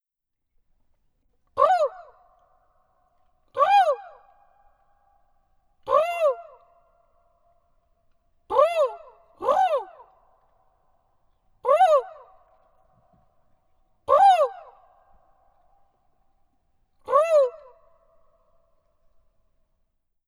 Kokeellisia huutoja Valkealan Olhavanvuoren kalliomaalauksen edustalla, 29 metrin päässä kalliosta. Huudot synnyttävät useita eri suunnista saapuvia kaikuja.